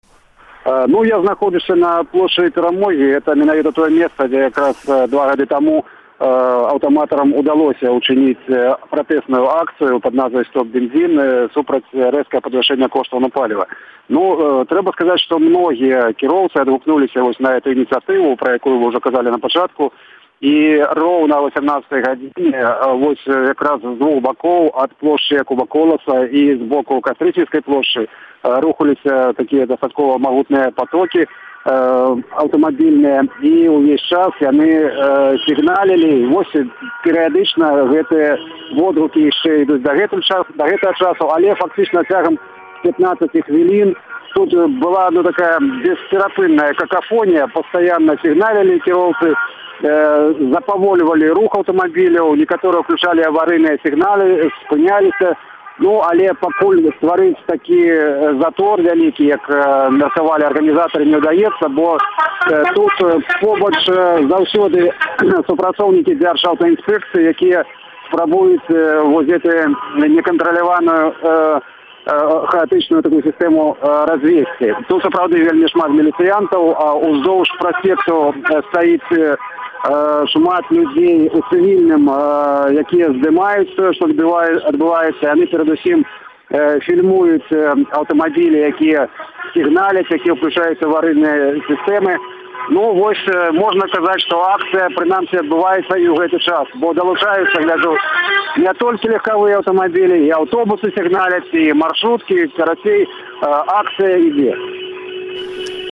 Рэпартаж
з акцыі «Стоп-падатак» у Менску